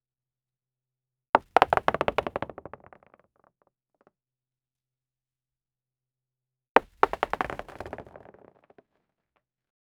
Heavy body falling on stone floor
heavy-body-falling-on-sto-cz26bzvd.wav